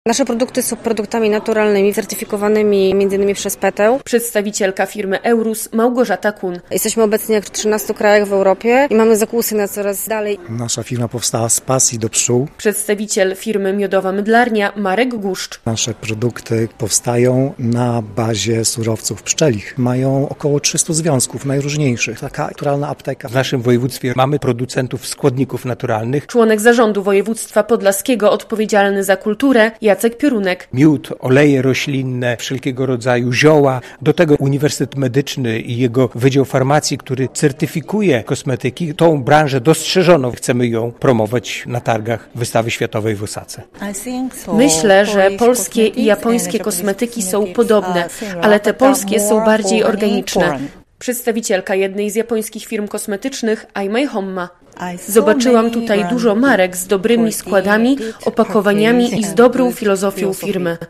Spotkanie z przedstawicielami branży kosmetycznej z Japonii przed EXPO 25 - relacja